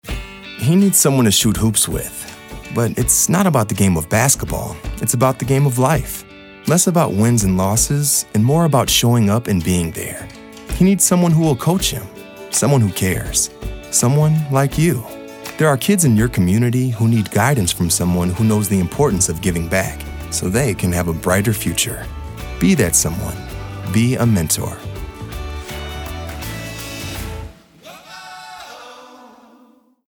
:30 Radio | Be A Mentor (The Game of Life)